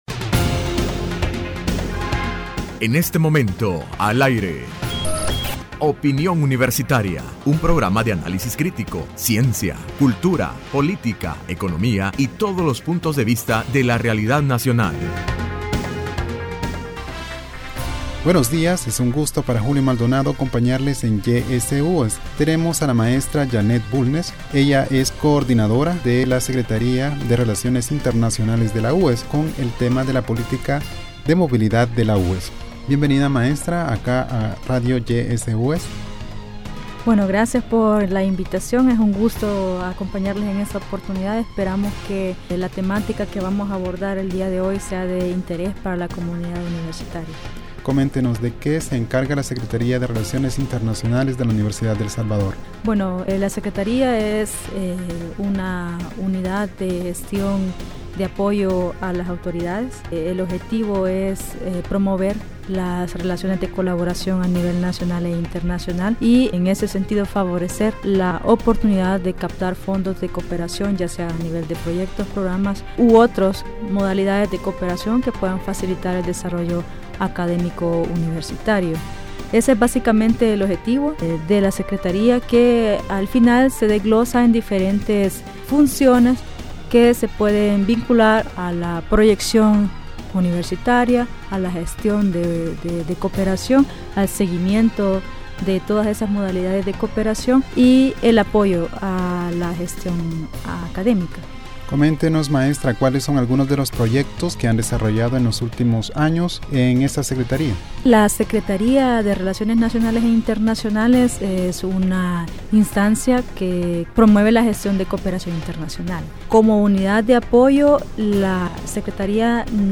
Entrevista Opinión Universitaria(18 Abril 2016)Política de Mobilidad en la UES.